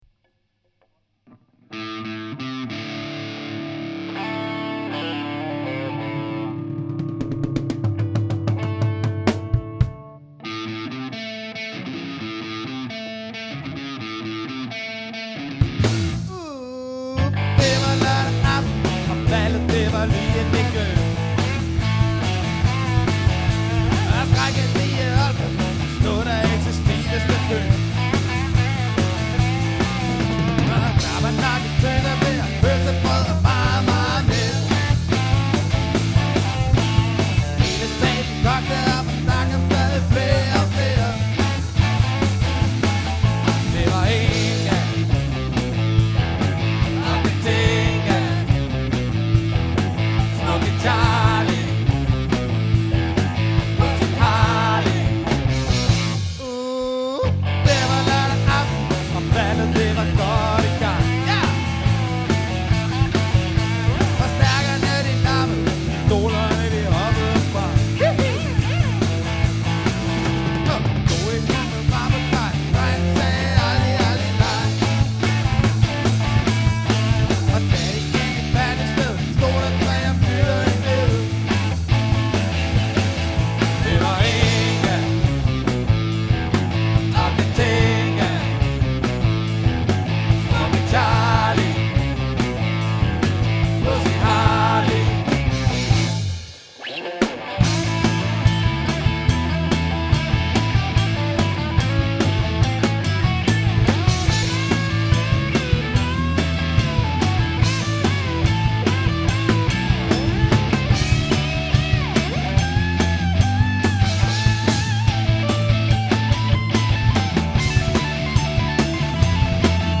• Rockband